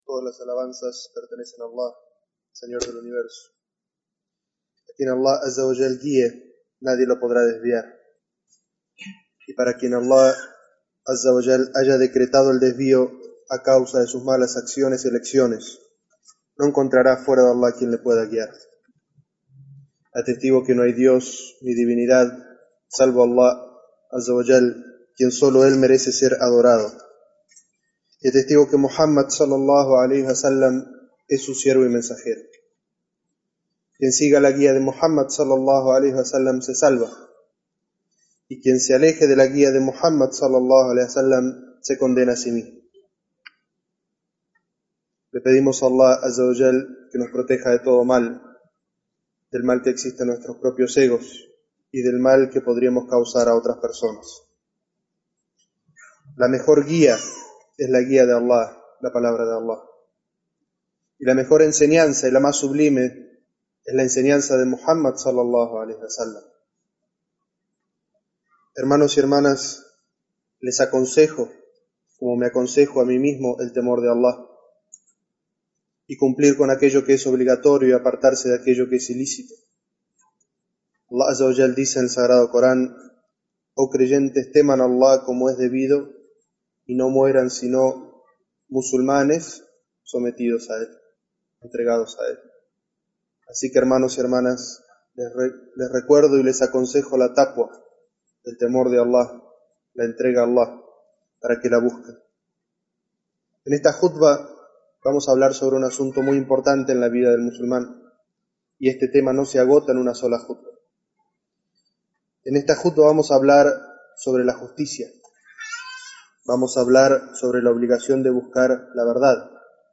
Jutbah Juzgar con la ley de Allah en nuestra vida privada y nuestra comunidad